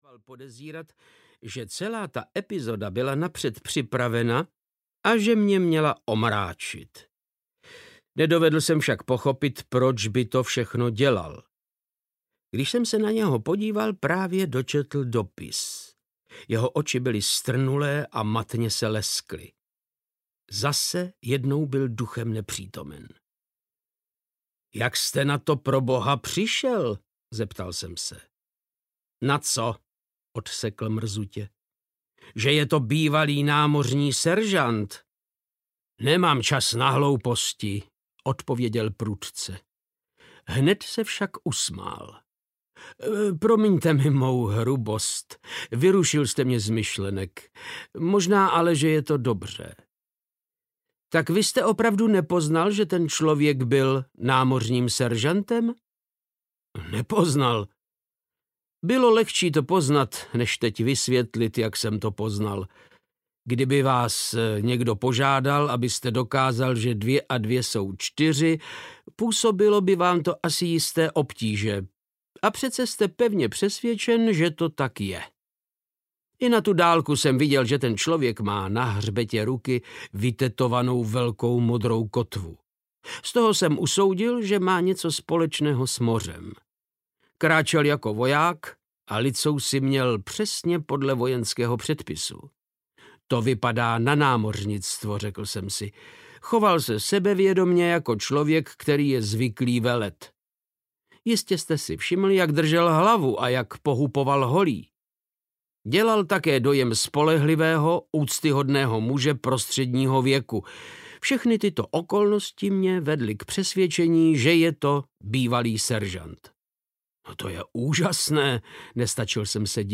Audiokniha Studie v šarlatové, kterou napsal Arthur Conan Doyle.
Ukázka z knihy
• InterpretVáclav Knop